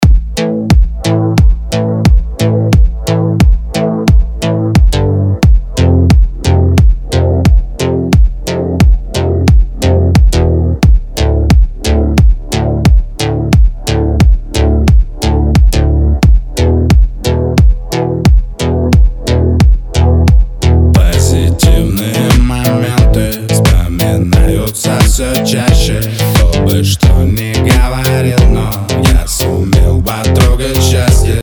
Рэп
громкие, Хип-хоп, ритмичные